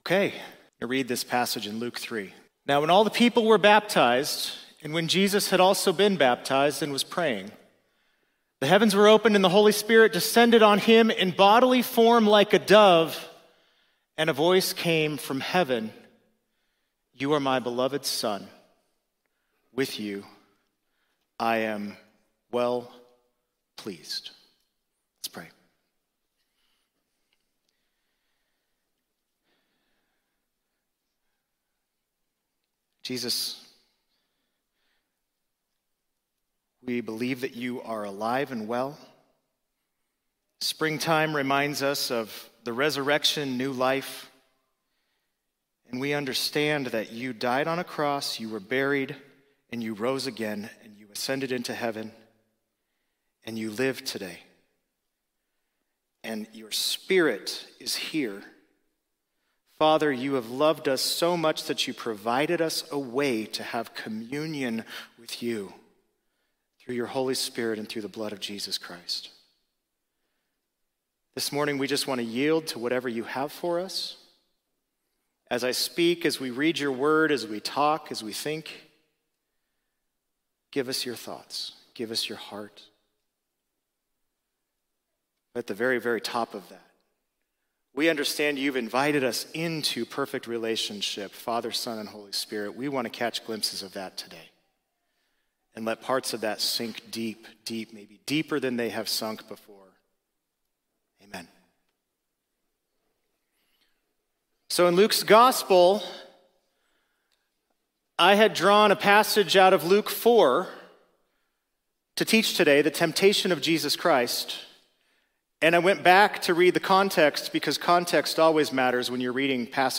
Sermons – Summitview Church